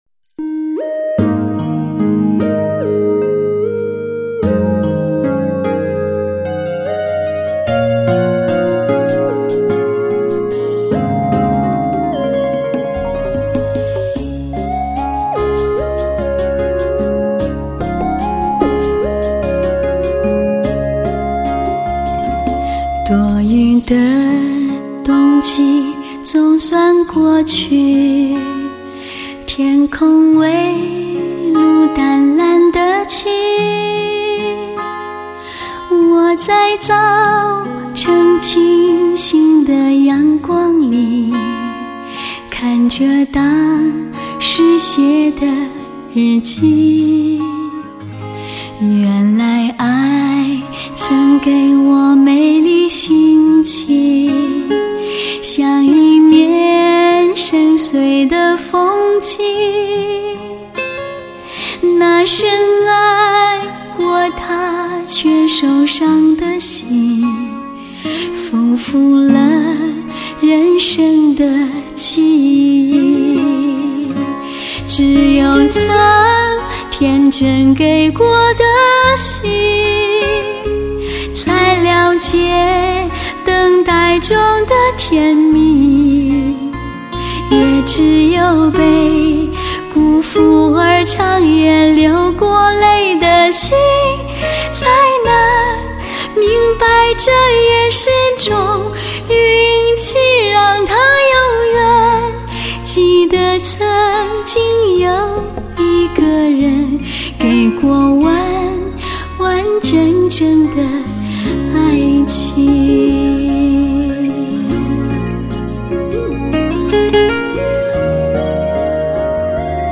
用最甜的声音，勾起你最美好的记忆，最让发烧友值得珍藏的唱片。